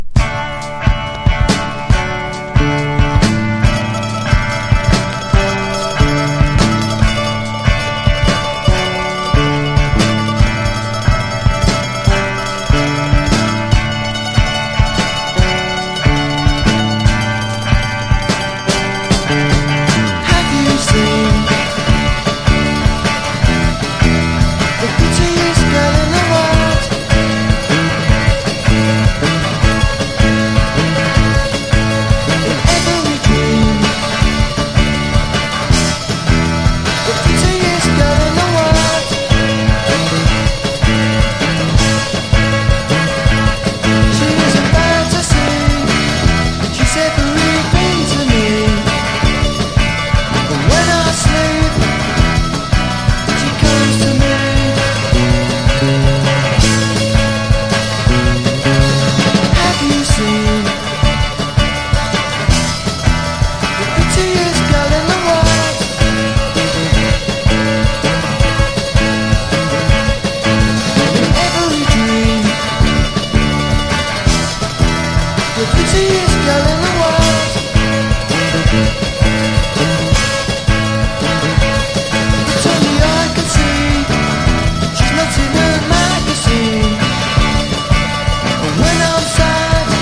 NEO ACOUSTIC / GUITAR POP# NEW WAVE# 80’s ROCK / POPS# PUNK